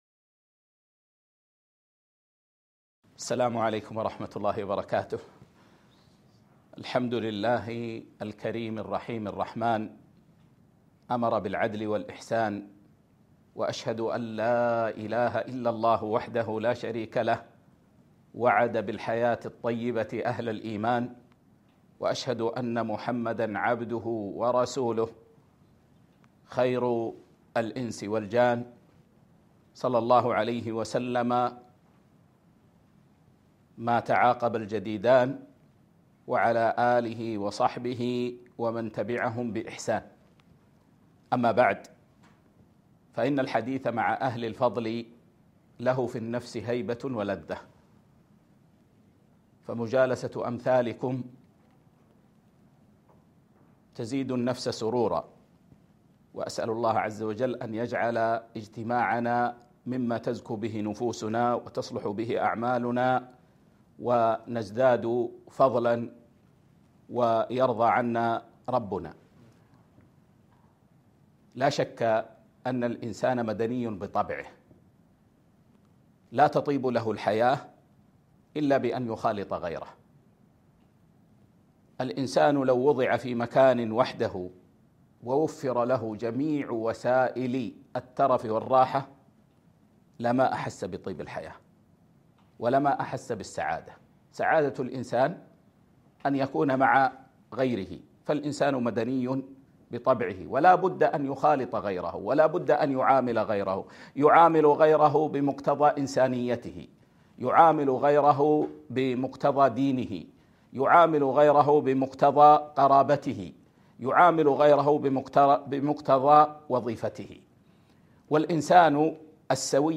محاضرة - الإنسان بين العدل والإحسان